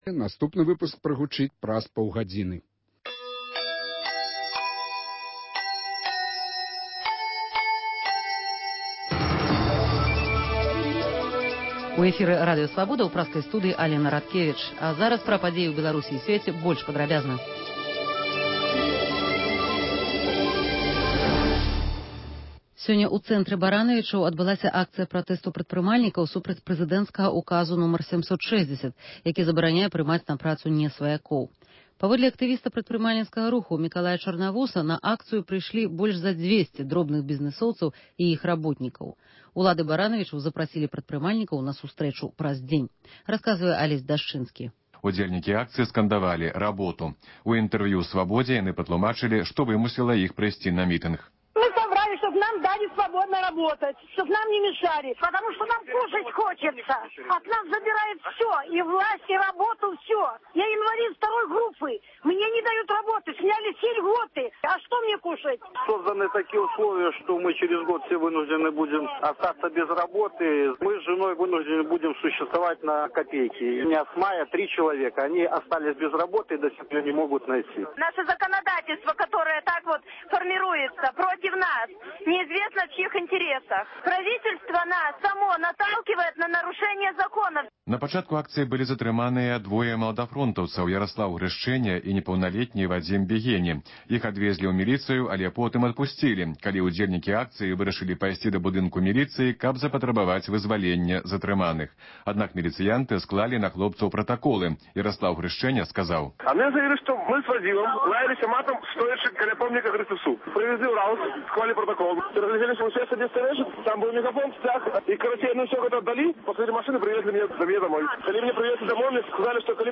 Паведамленьні карэспандэнтаў "Свабоды", званкі слухачоў, апытаньні на вуліцах беларускіх гарадоў і мястэчак.